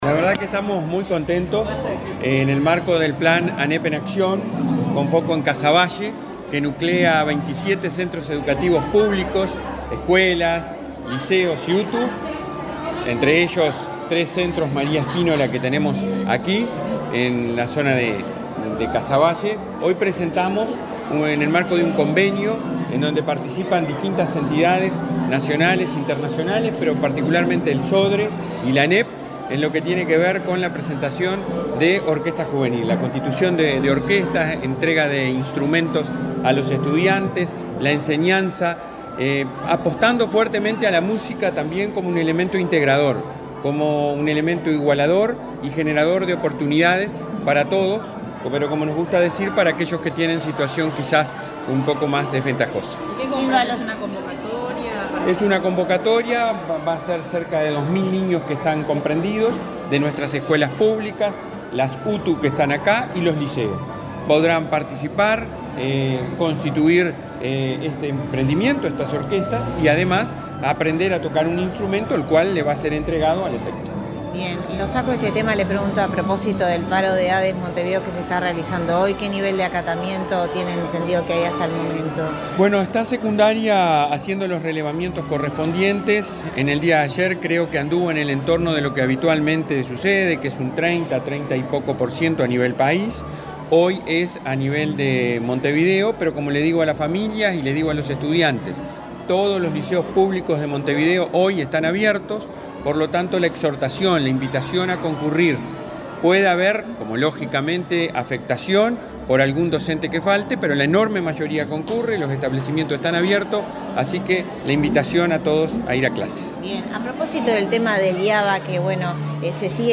Declaraciones del presidente de la ANEP, Robert Silva
Declaraciones del presidente de la ANEP, Robert Silva 14/04/2023 Compartir Facebook X Copiar enlace WhatsApp LinkedIn El presidente de la ANEP, Robert Silva, participó, en la escuela 178 de Montevideo, en el lanzamiento del proyecto Música en Apoyo a los Procesos Educativos. Luego dialogó con la prensa.